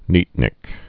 (nētnĭk)